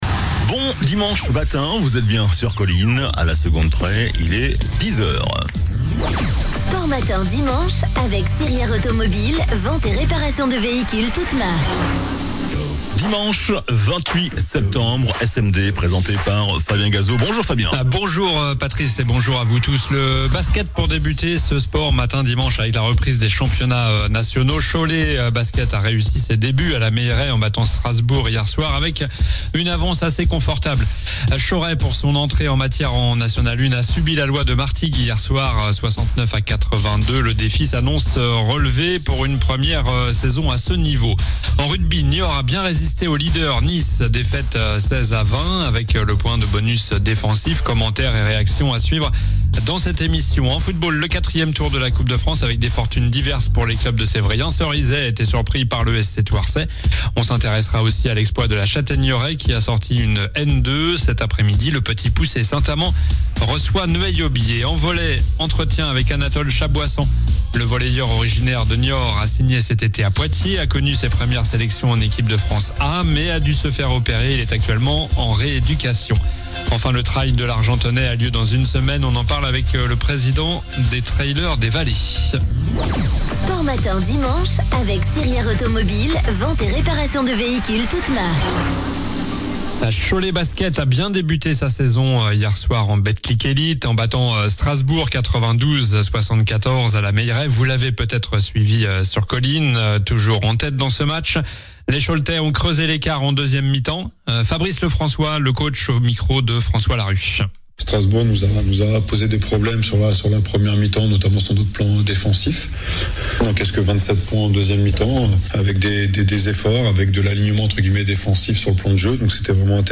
En volley, entretien